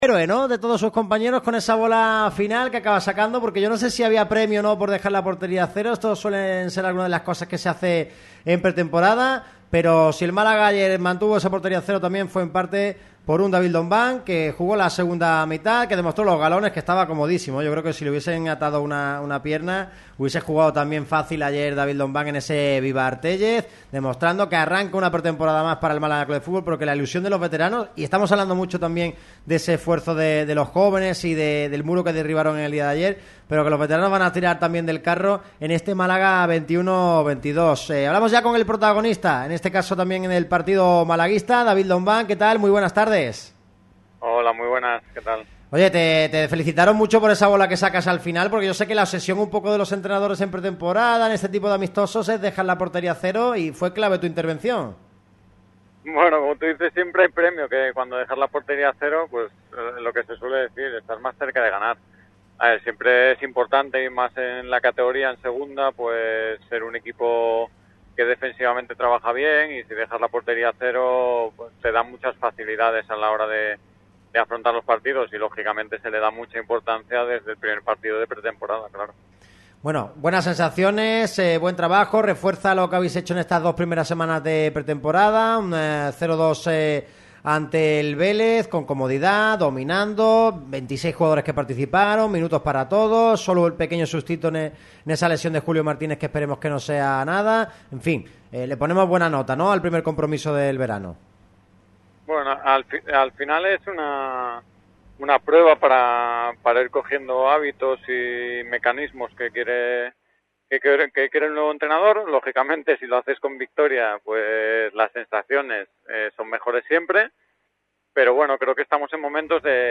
Un Lombán que atendió a Radio MARCA Málaga, charló sobre la pretemporada del conjunto de Martiricos y dio los detalles del nuevo Málaga de José Alberto.